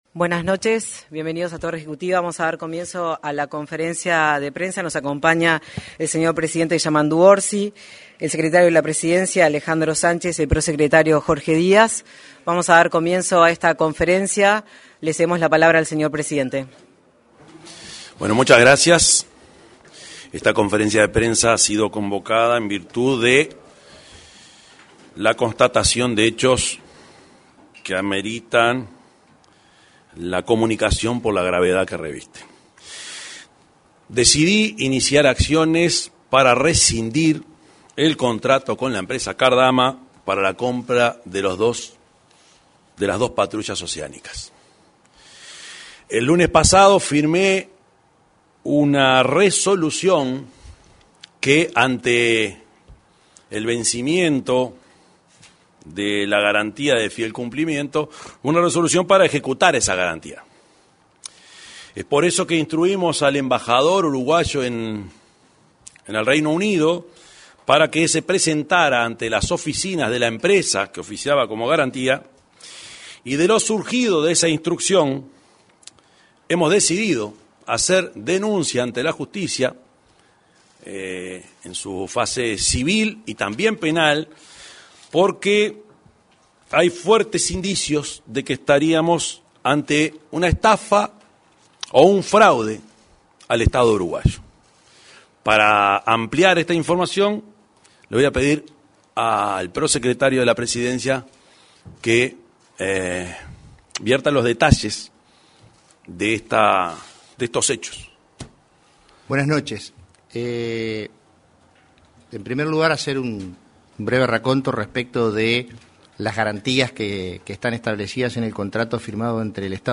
Conferencia de prensa del presidente de la República y autoridades del Gobierno
Conferencia de prensa del presidente de la República y autoridades del Gobierno 22/10/2025 Compartir Facebook X Copiar enlace WhatsApp LinkedIn Este miércoles 22 se realizó una conferencia de prensa en Torre Ejecutiva. En la oportunidad, se expresaron, el presidente de la República, Yamandú Orsi; el prosecretario de la Presidencia, Jorge Díaz, y el secretario de la Presidencia, Alejandro Sánchez.